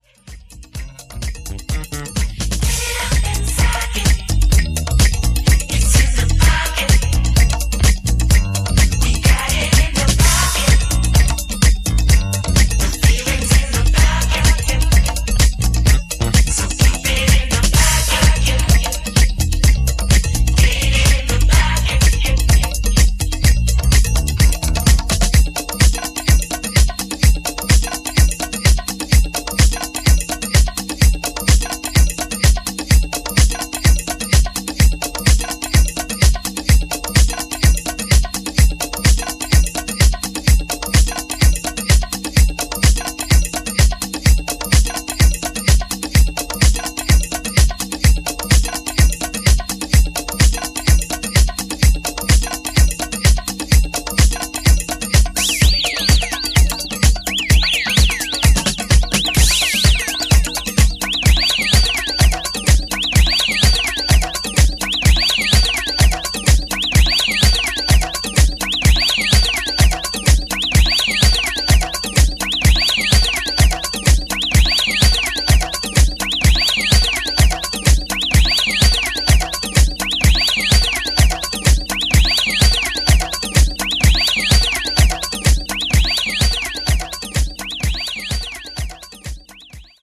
執拗な弄りは抑えめで、原曲を尊重した仕上がりとなっています。